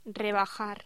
Locución: Rebajar
locución
Sonidos: Voz humana